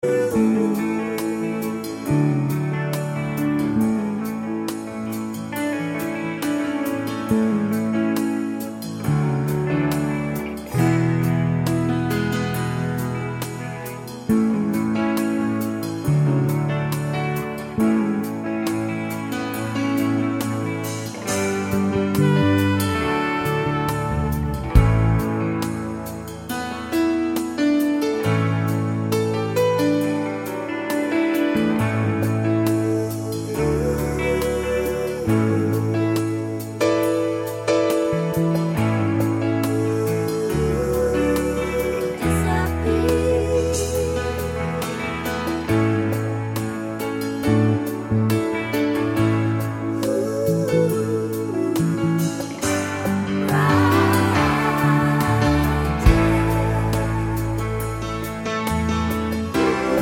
no Backing Vocals Irish 4:47 Buy £1.50